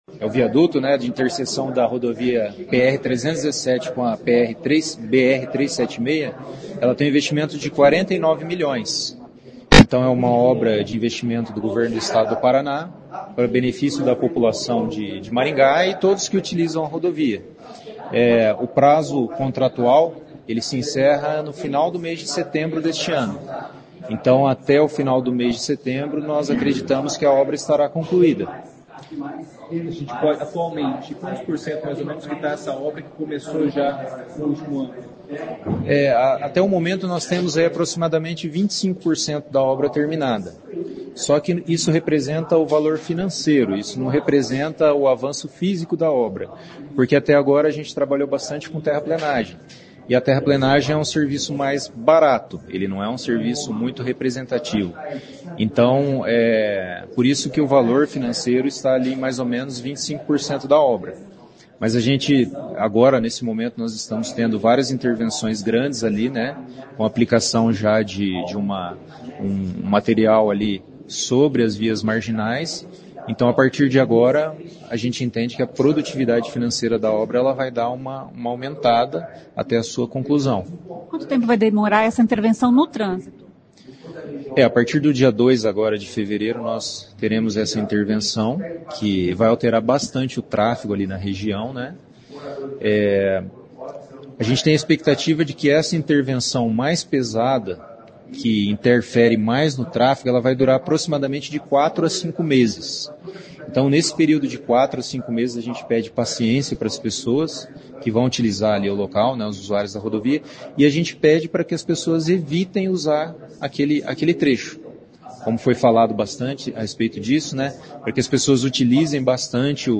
Além de sinalização e fiscalização, o DER terá guincho para retirar das vias com rapidez veículos quebrados ou acidentados, explica o superintendente regional do DER, Darlan Santana.